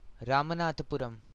pronunciation), also known as Ramnad,[1] is a city Municipal Corporation in Ramanathapuram district in the Indian state of Tamil Nadu.
Ramanathapuram.ogg.mp3